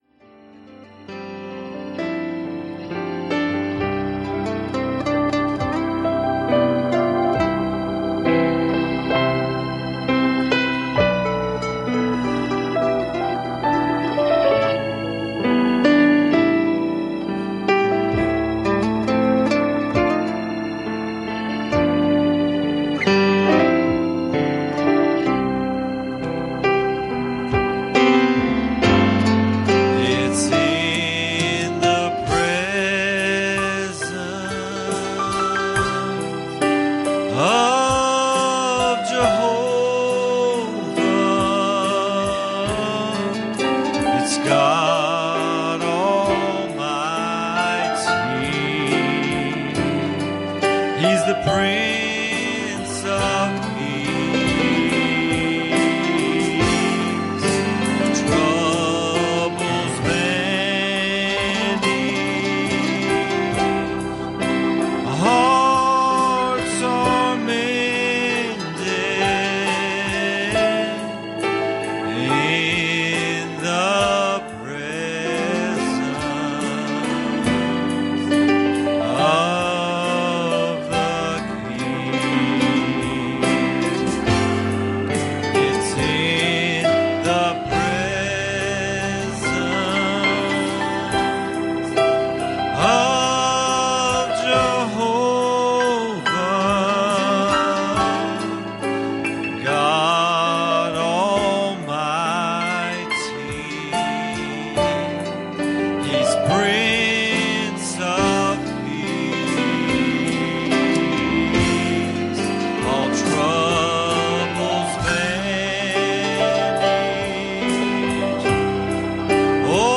Passage: Numbers 20:7 Service Type: Wednesday Evening